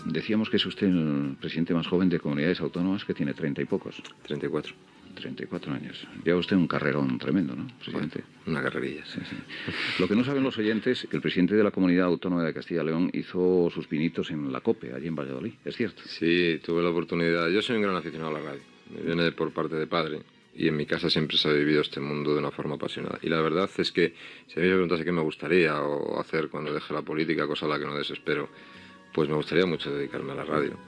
Fragment d'una entrevista a José María Aznar, president de la Comunidad Autónoma de Castilla y León.
Info-entreteniment